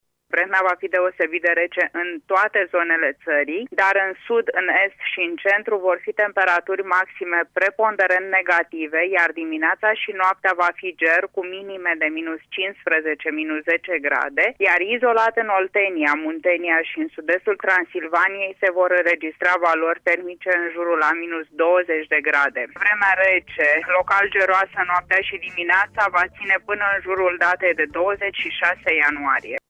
Gerul va ține toată săptămâna, iar minimele vor ajunge până la -20 de grade în depresiunile din estul Transilvaniei, spune meteorologul